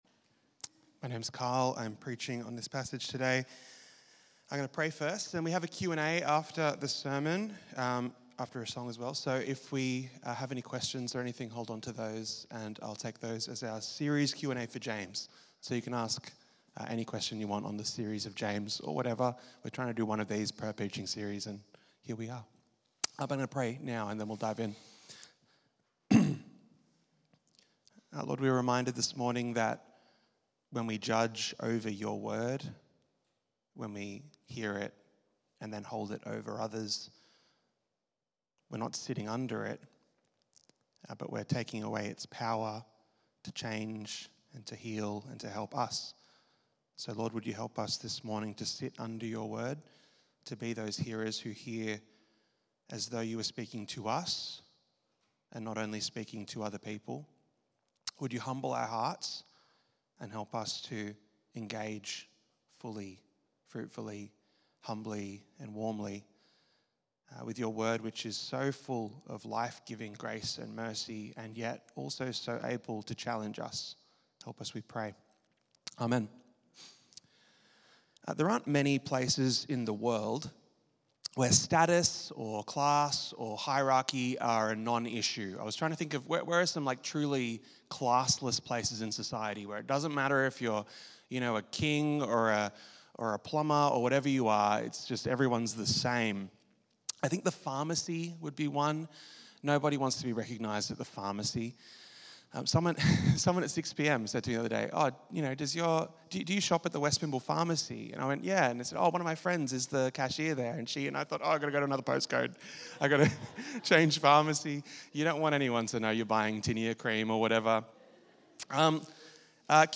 The sermon, based on James 3:13-4:16, explores self-interested authority and ambition within the church. It highlights how worldly distinctions, such as wealth and status, can creep into the church, leading to jostling for power and subverting the gospel message. The sermon emphasises the importance of humility and genuine wisdom, rather than worldly wisdom, in church leadership.